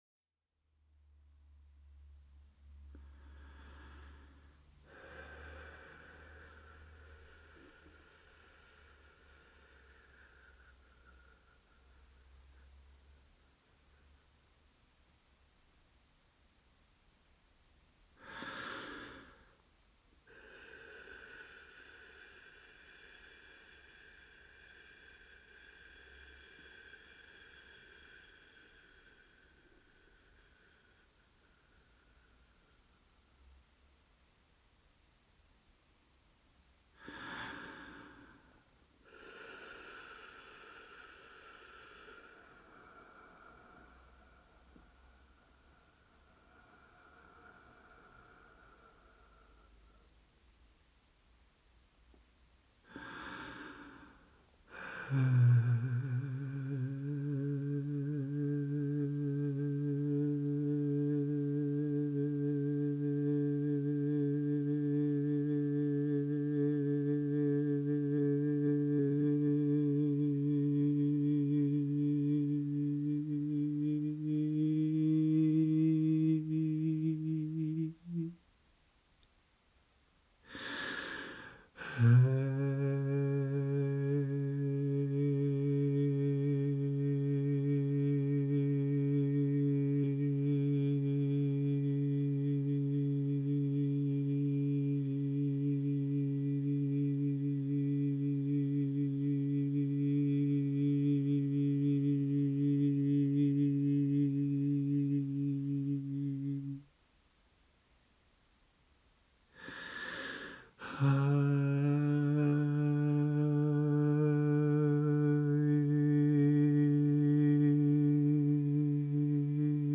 International Publisher and label for New experimental Music
voice